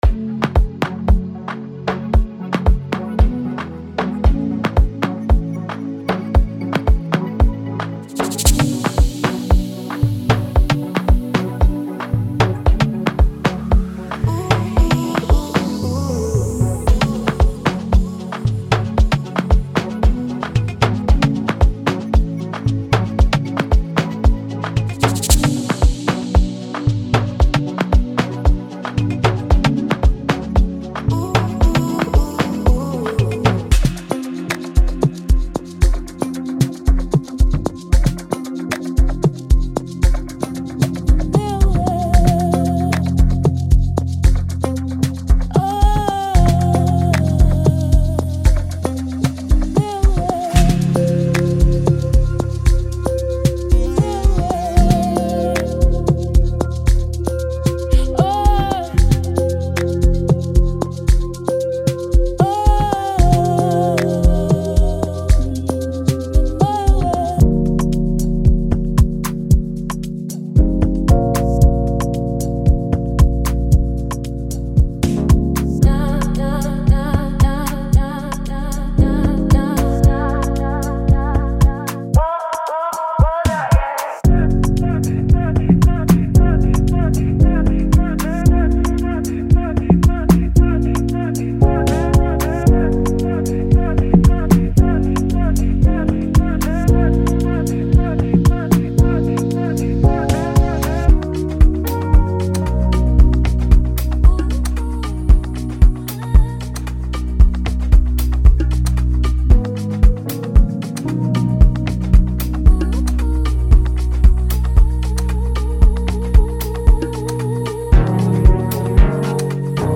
– 40+ Chord & Melody Loops
– 90+ drum and percussion loops